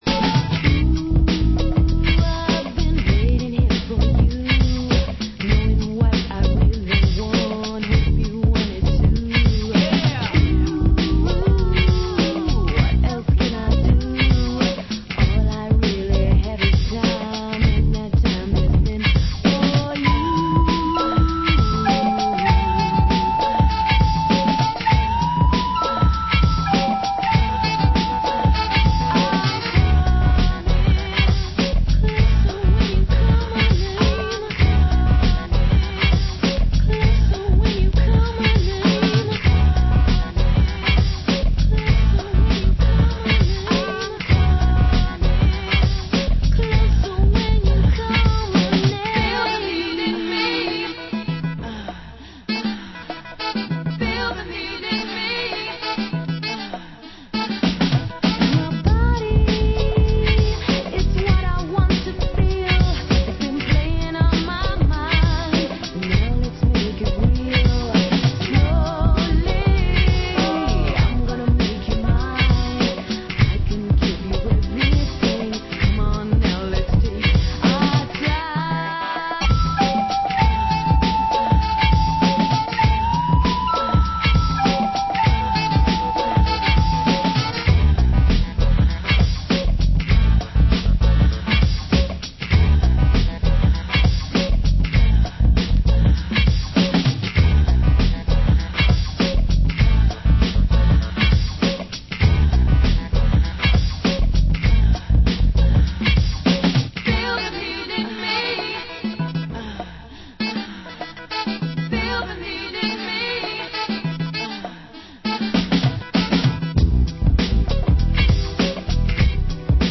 Genre: Down Tempo